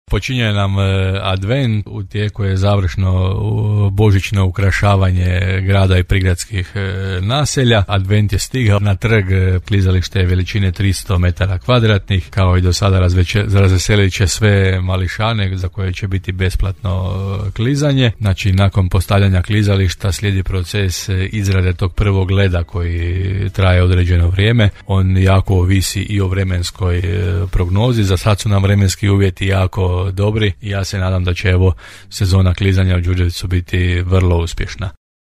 -rekao je ranije za Podravski radio gradonačelnik Hrvoje Janči.